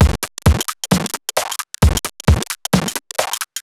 Index of /musicradar/uk-garage-samples/132bpm Lines n Loops/Beats
GA_BeatDCrush132-03.wav